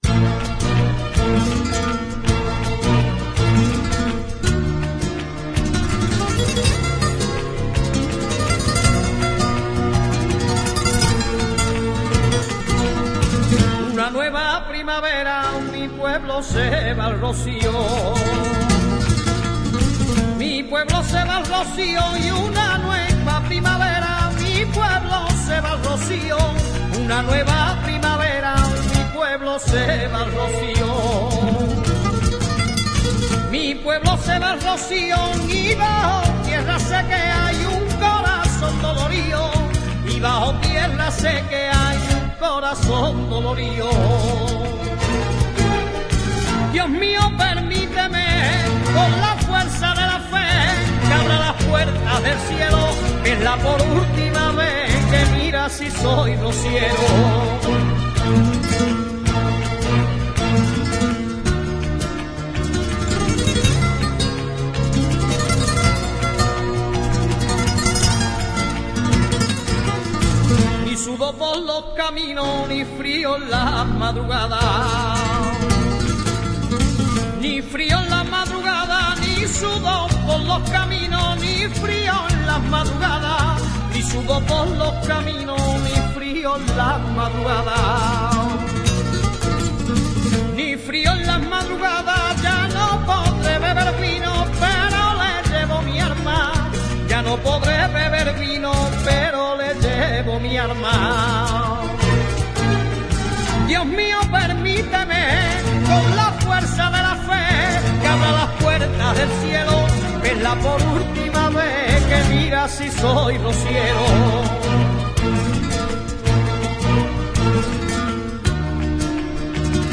Guitarras
Temática: Rociera